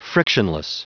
Prononciation du mot frictionless en anglais (fichier audio)